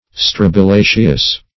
Search Result for " strobilaceous" : The Collaborative International Dictionary of English v.0.48: Strobilaceous \Strob`i*la"ceous\, a. [See Strobila .]